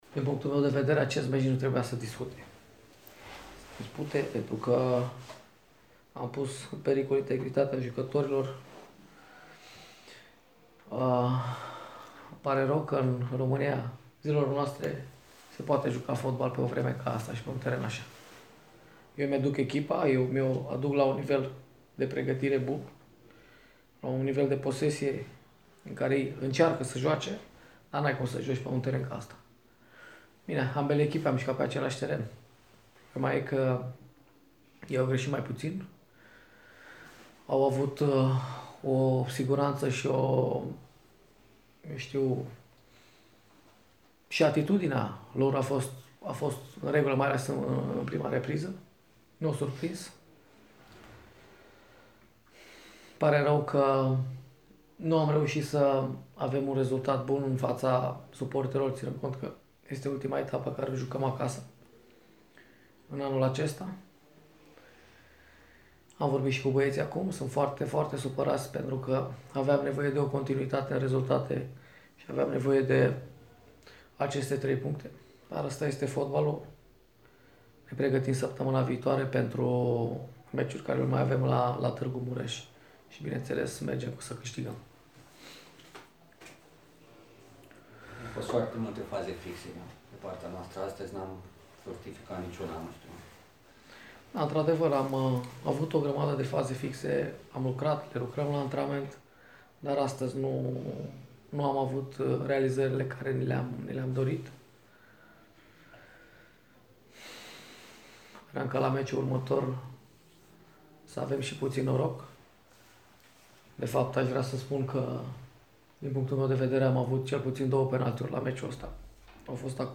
În conferinţa de presă care a urmat partidei, tehnicianul sucevenilor, Florentin Petre, a declarat că, după părerea sa, meciul nu ar fi trebuit să se dispute, întrucât starea terenului a pus în pericol intregritatea jucătorilor: